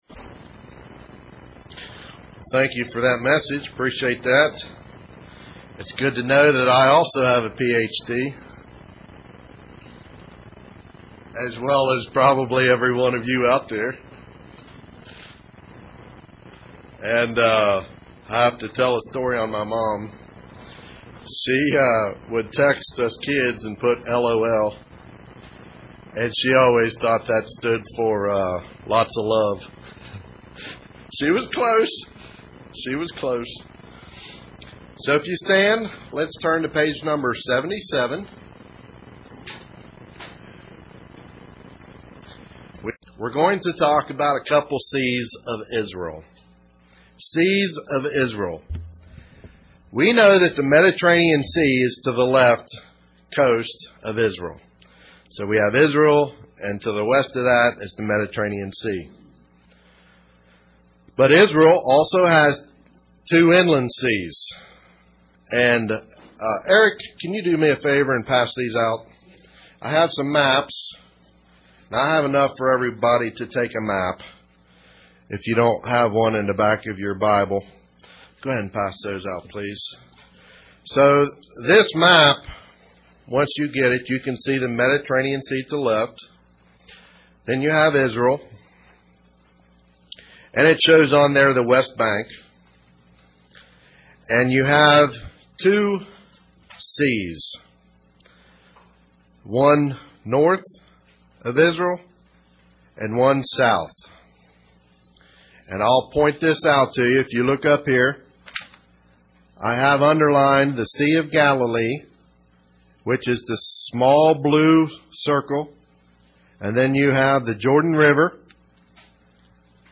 Given in Paintsville, KY